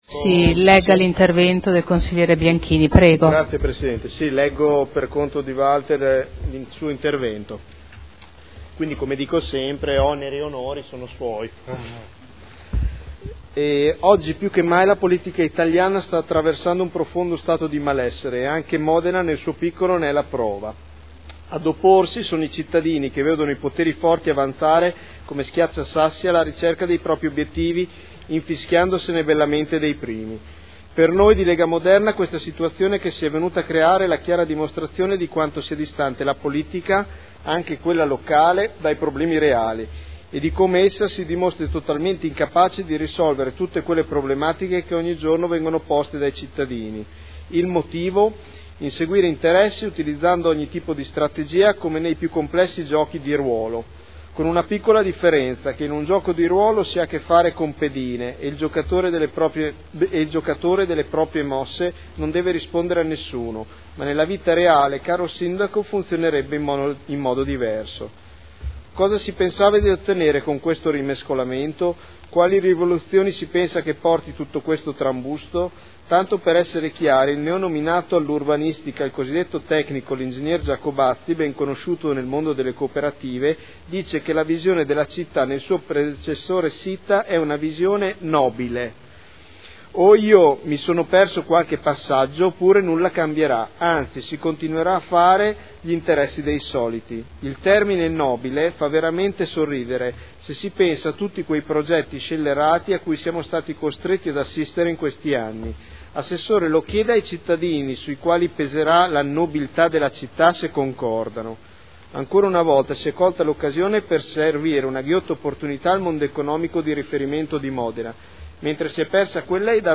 Seduta del 23/04/2012. Dibattito su comunicazione del Sindaco sulla composizione della Giunta.